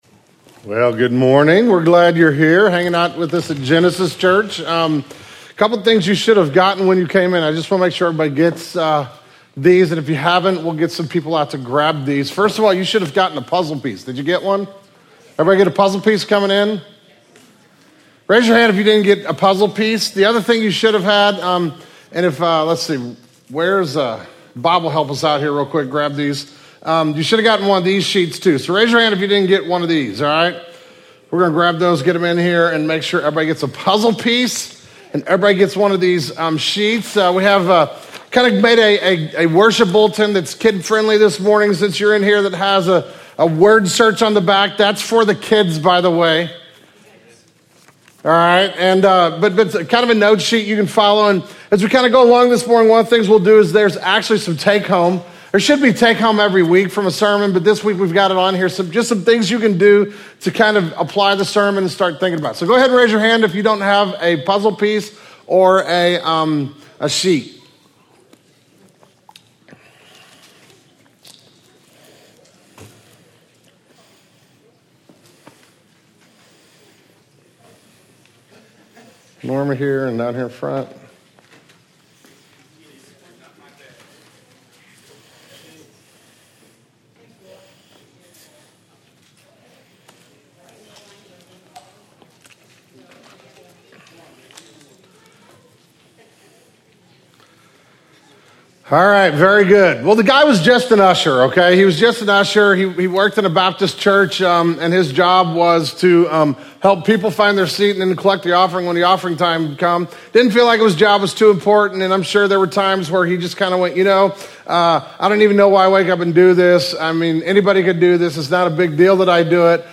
This morning in our sermon, and this week in our reading from the Purpose Driven Life you the God-given S.H.A.P.E. you have and begin thinking about God's call to ministry for your life.